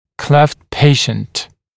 [kleft ‘peɪʃnt][клэфт ‘пэйшнт]пациент с расщелиной (губы, нёба)